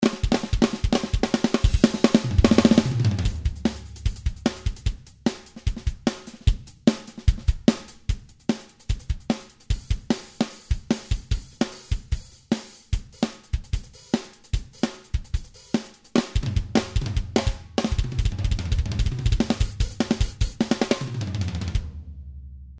test-ezdrummer.mp3